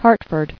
[Hart·ford]